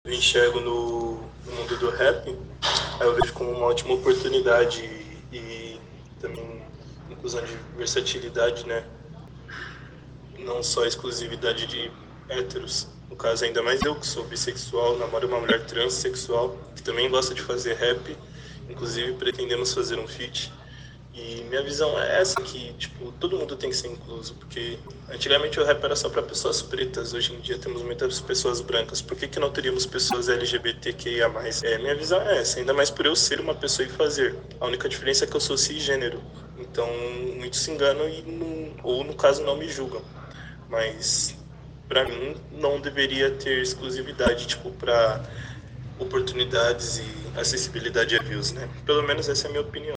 declaração